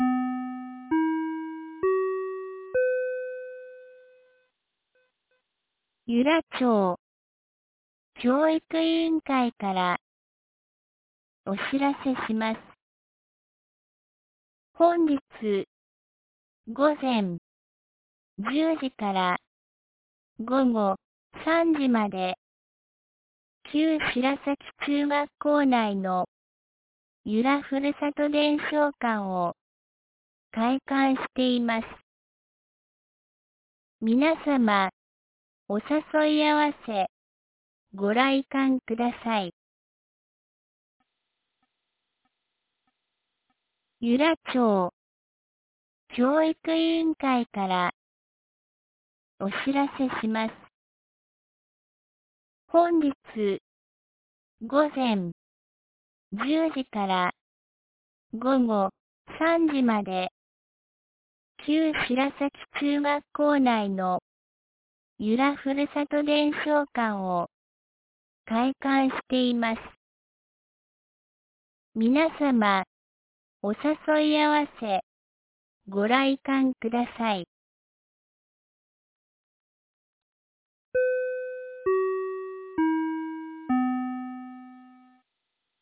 2025年05月03日 07時51分に、由良町から全地区へ放送がありました。